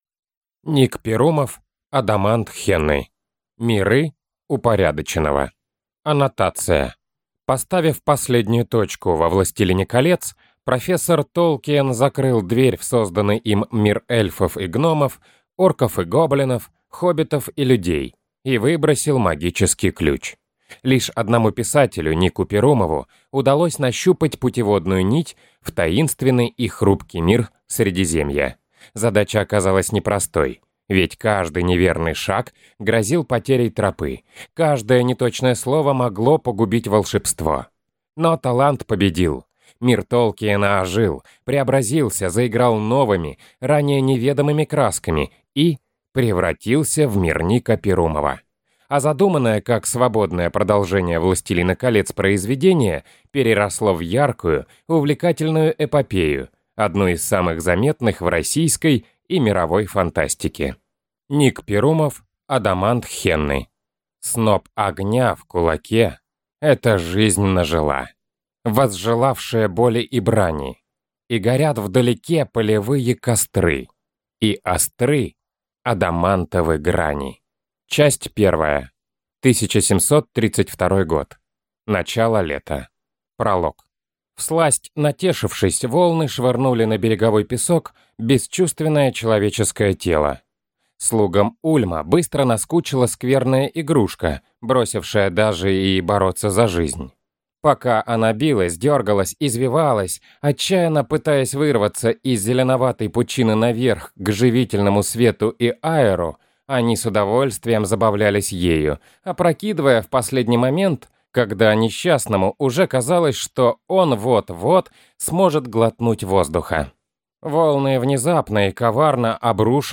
Аудиокнига Адамант Хенны | Библиотека аудиокниг